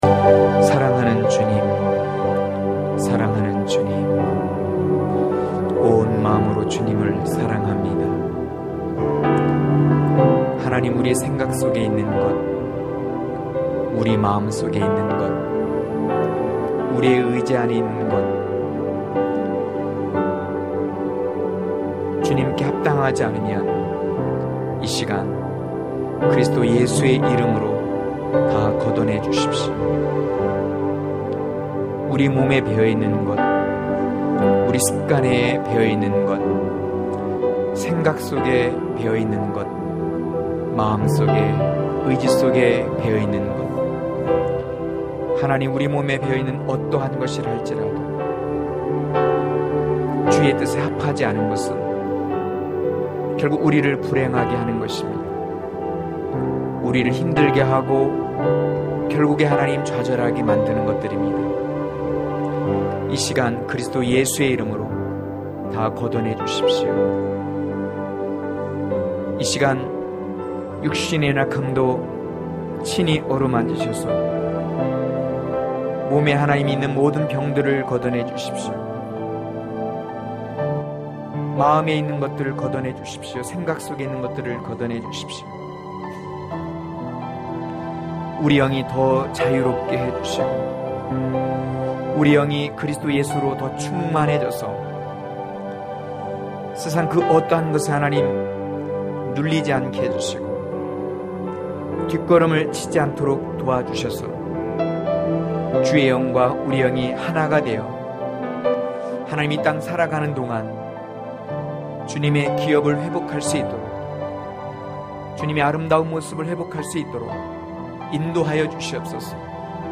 강해설교 - 08.여우를 잡자!!!(아2장13-17절)